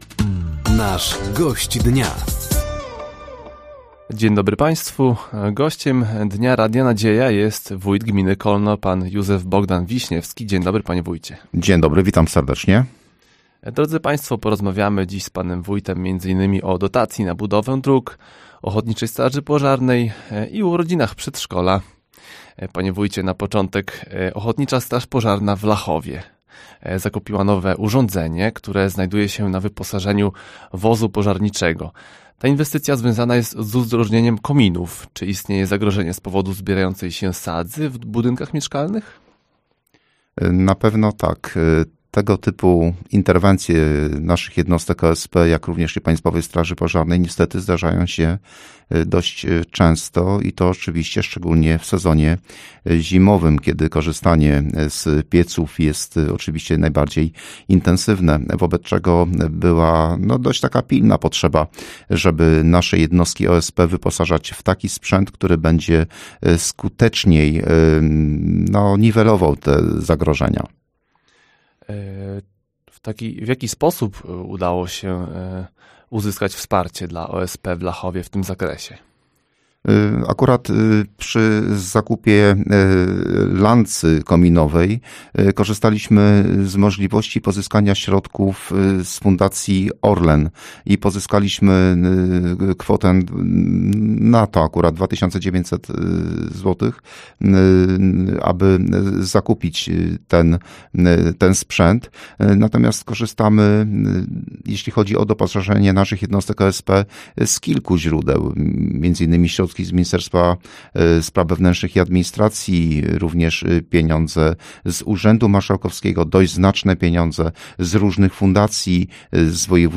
“Gościem Dnia” Radia Nadzieja był Józef Bogdan Wiśniewski, Wójt Gminy Kolno. Rozmowa dotyczyła inwestycji drogowych, wsparcia finansowego, bezpieczeństwa i aktywizacji społeczeństwa.